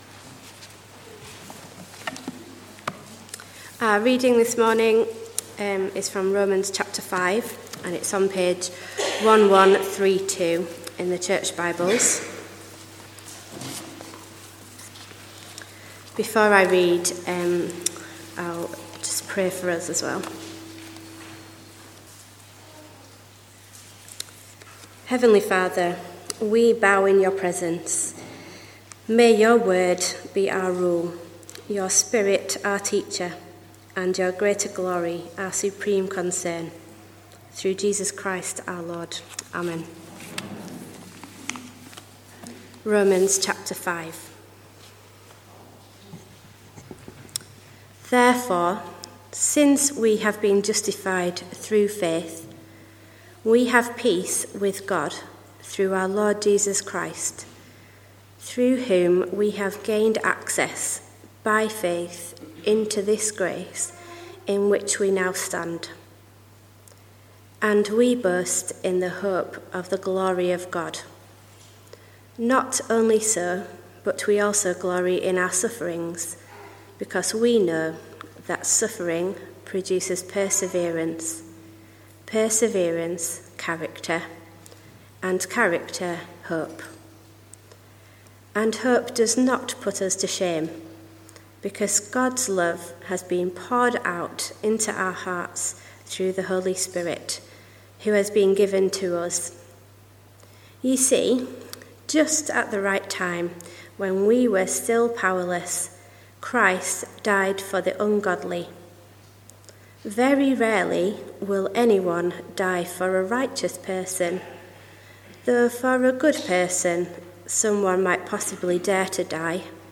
Romans 5 – Remembrance Day Service